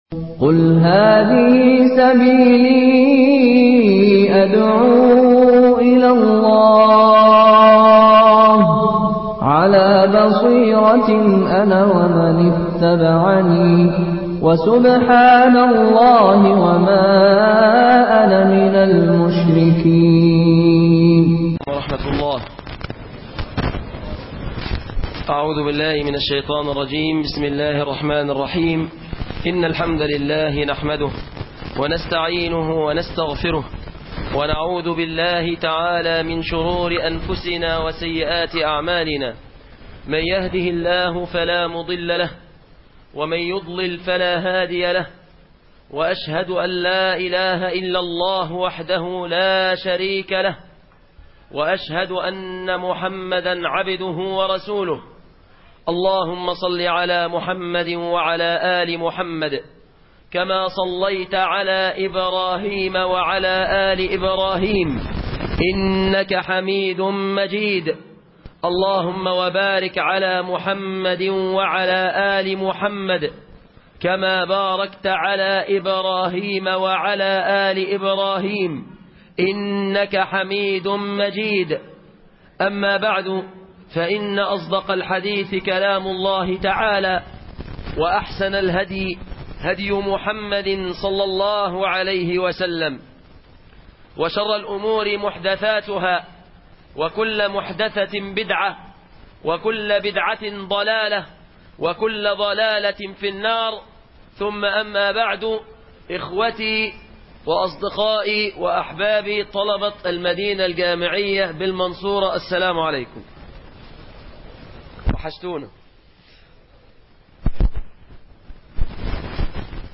ندوة بالمدينة الجامعية بالمنصورة